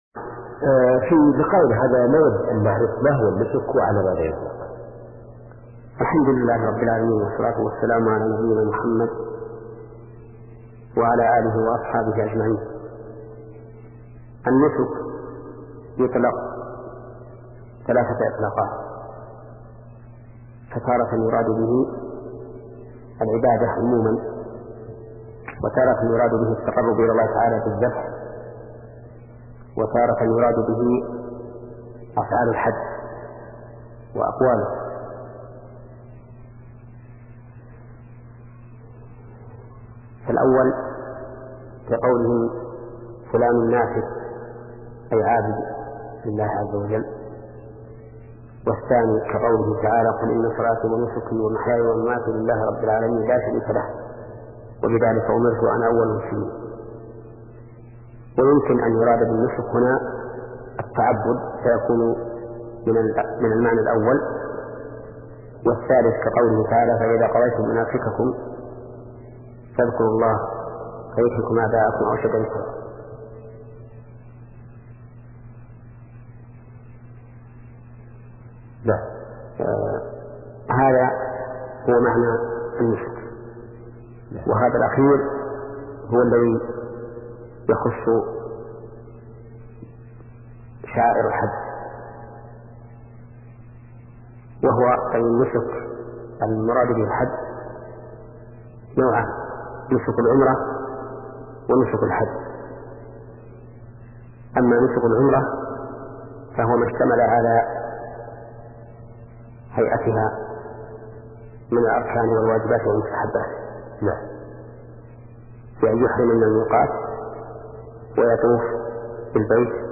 فقه العبادات [37] - للشيخ : ( محمد بن صالح العثيمين ) الحج ركن من أركان الإسلام، وهو فرض بالإجماع على المسلم البالغ العاقل الحر، القادر على تكاليف الحج ..، واختلف في حكم العمرة، والصحيح أنها واجبة، ووجوب الحج والعمرة في العمر مرة واحدة، وهو على الفور.